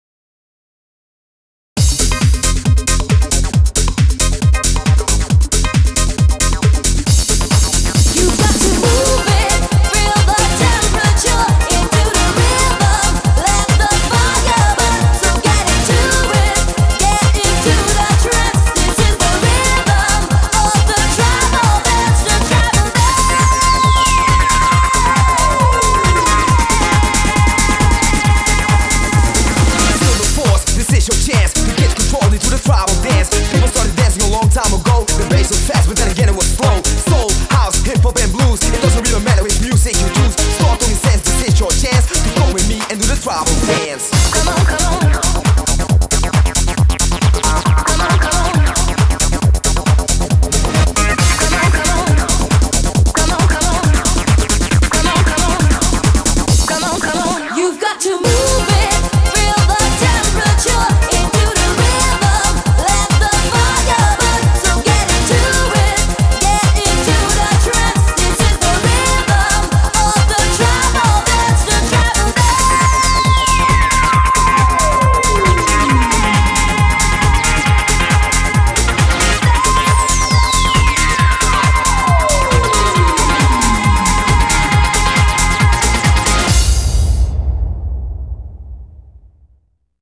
BPM136
Audio QualityMusic Cut